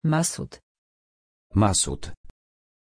Aussprache von Masud
pronunciation-masud-pl.mp3